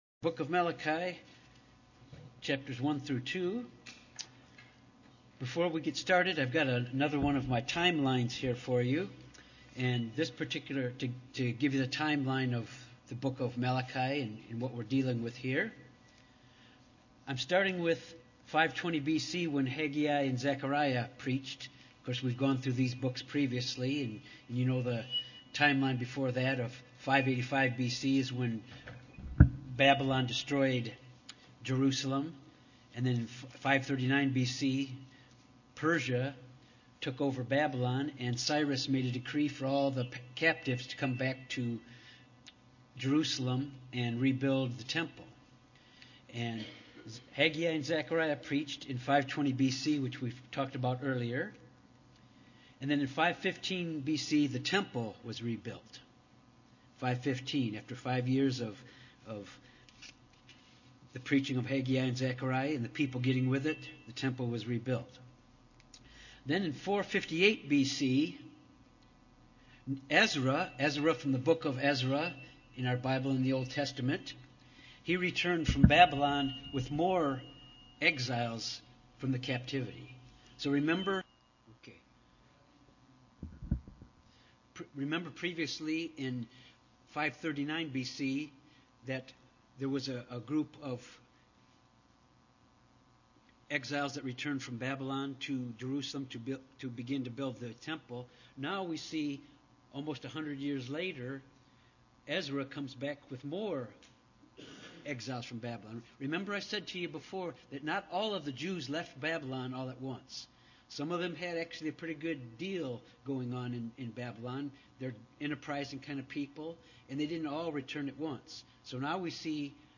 Bible Study on chapters 1 and 2 of Malachi.